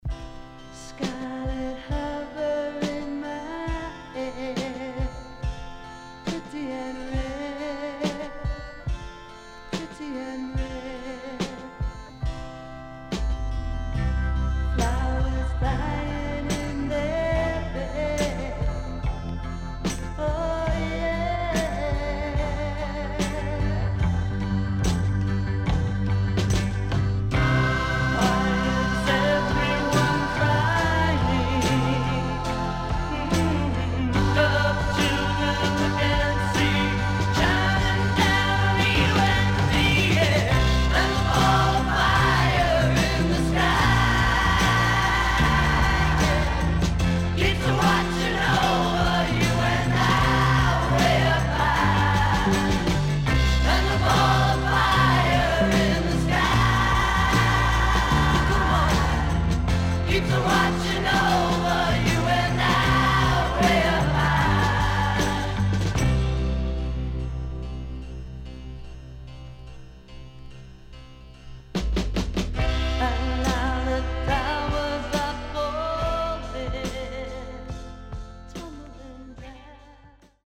CONDITION SIDE A:VG+〜EX-
SIDE A:少しチリノイズ入りますが良好です。